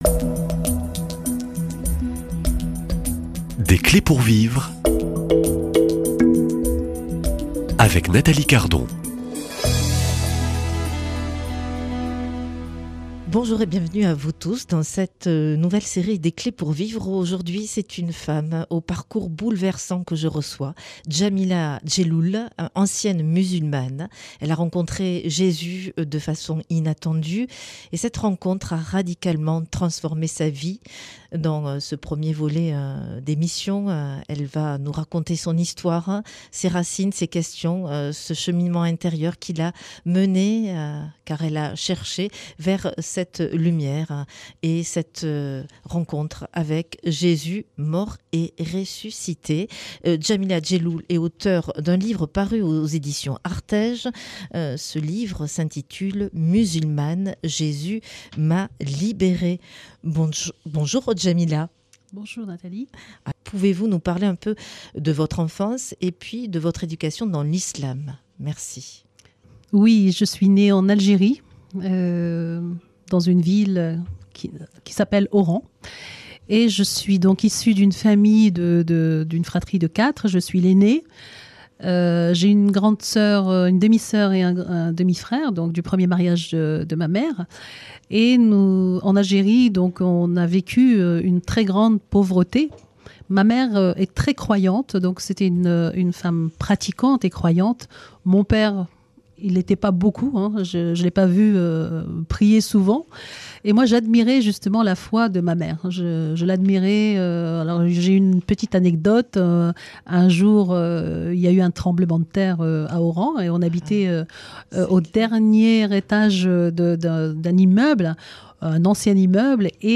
C’est une femme au parcours bouleversant que je reçois